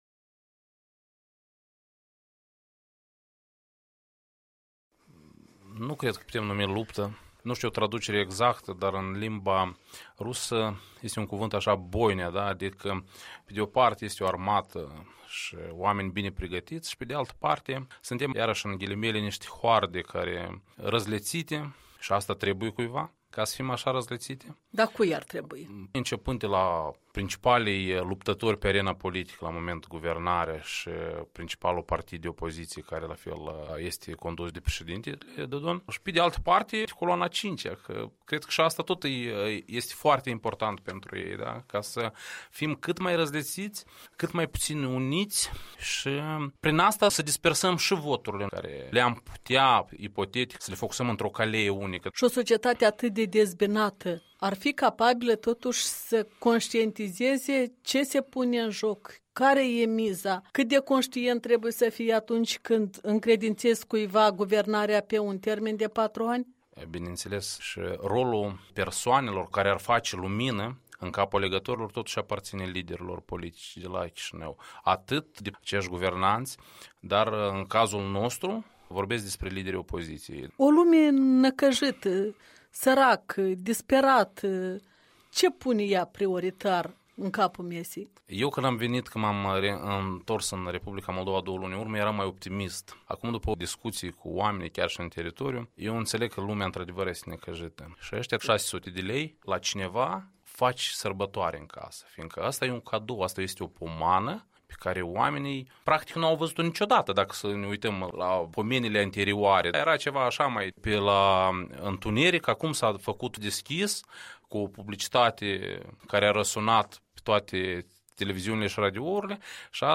Despre alegerile parlamentare și contextul în care se pregătesc, o discuție cu un moldovean plecat în Statele Unite și revenit acasă după șase ani.